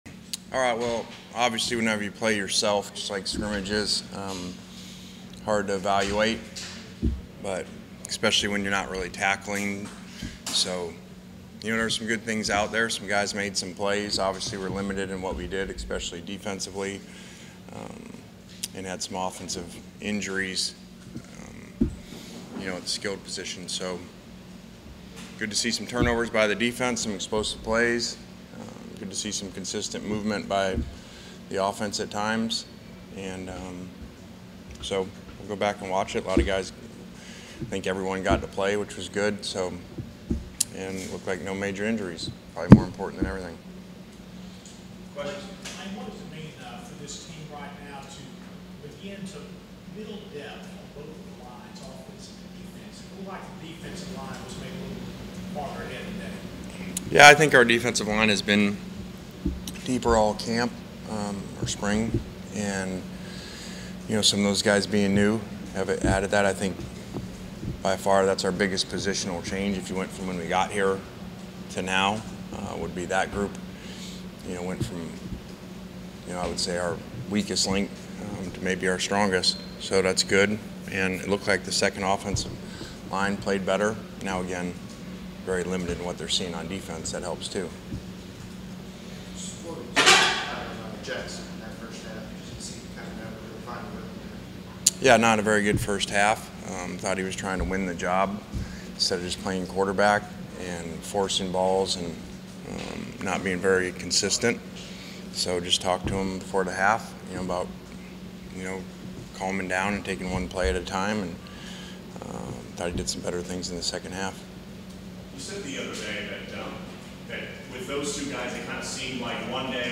Ole Miss had their annual spring game over the weekend and all eyes were on the quarterback competition. Lane Kiffin met with the media to recap what he saw from his team after spring practice.